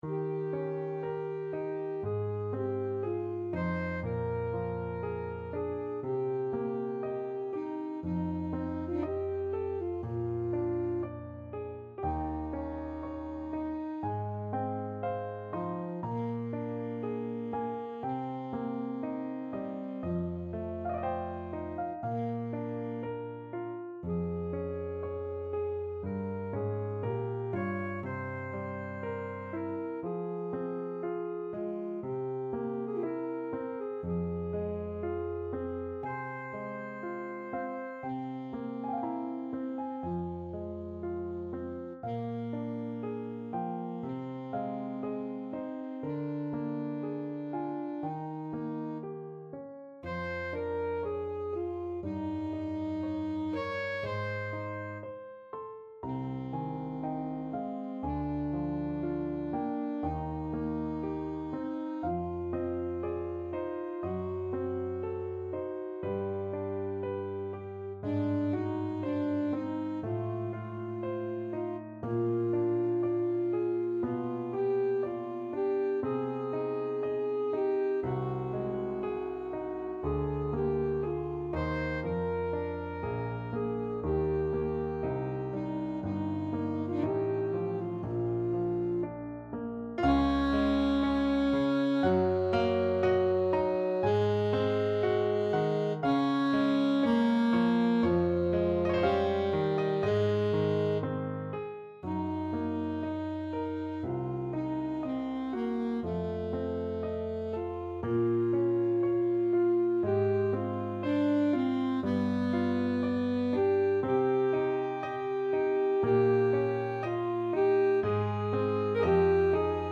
Alto Saxophone
~ = 60 Largo
3/2 (View more 3/2 Music)
Classical (View more Classical Saxophone Music)